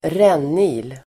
Ladda ner uttalet
Uttal: [²r'en:il]